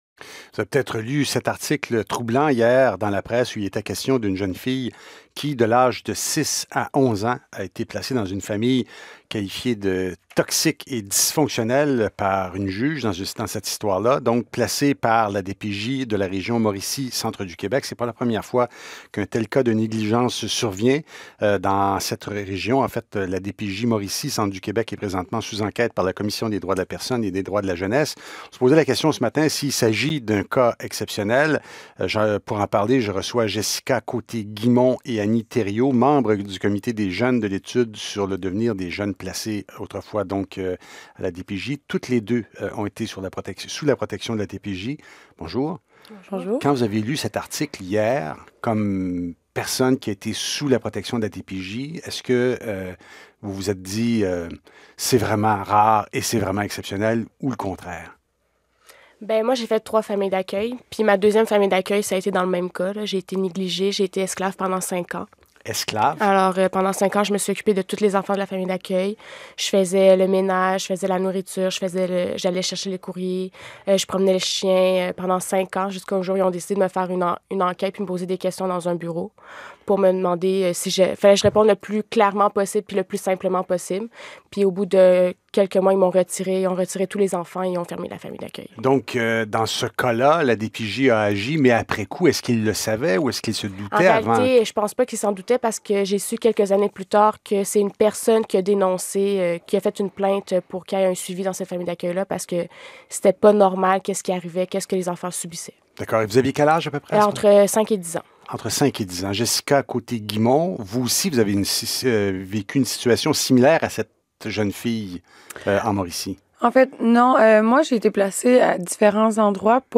Entrevue avec des membres du Comité des jeunes